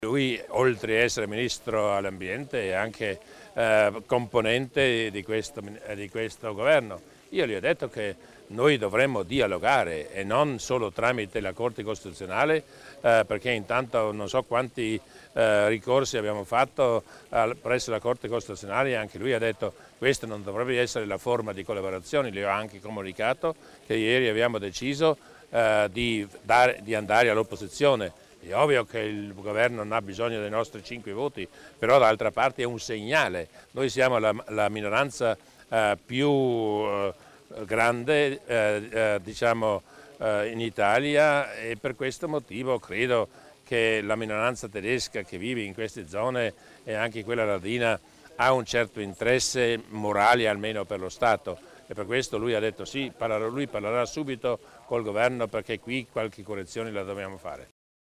Il Presidente Durnwalder illustra i temi trattati insieme al Ministro Clini